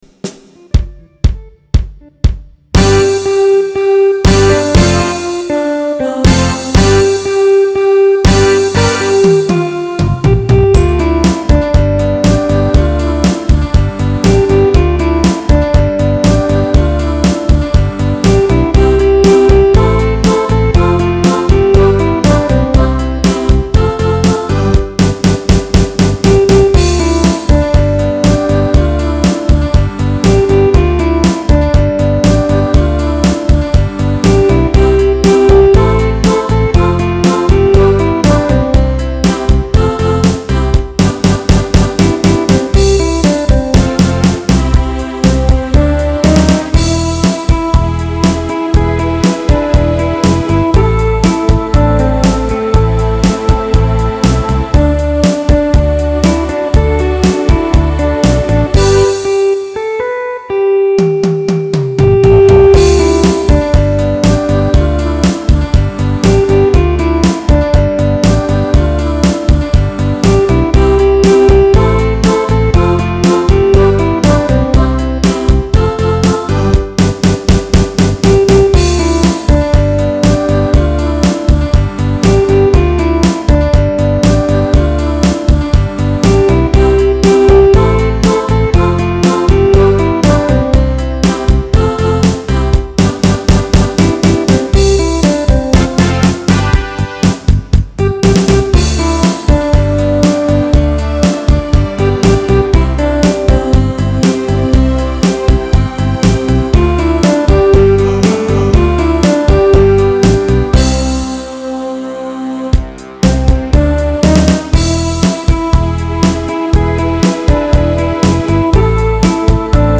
(Pop-Rock category)